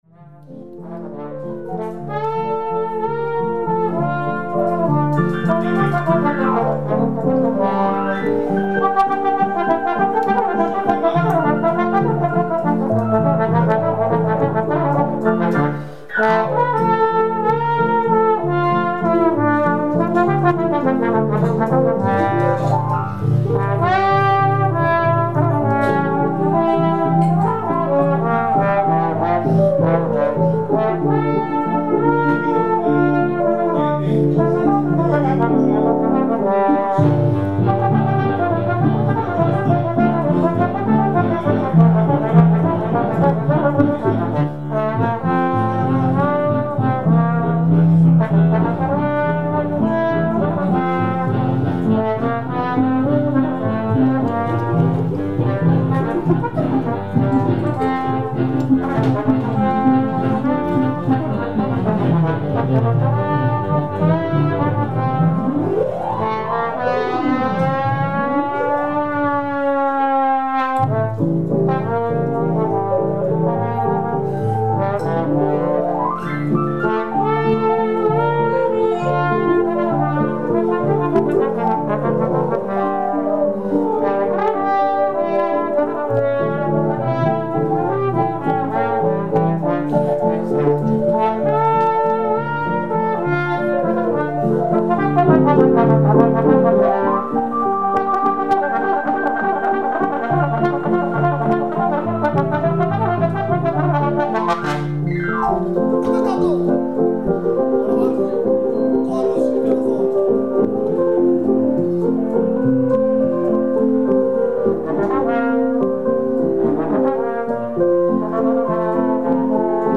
Trombone and keyboard duet
aristo_trombone.mp3